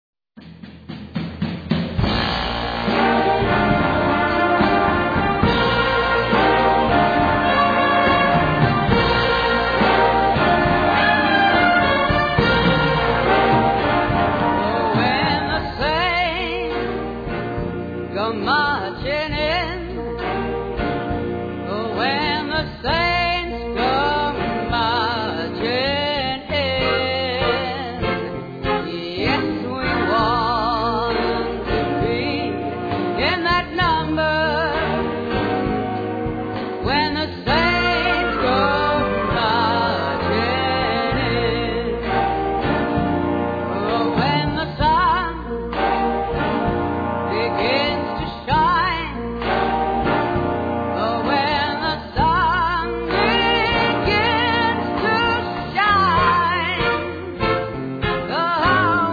Gattung: für Solo Gesang und Blasorchester
Besetzung: Blasorchester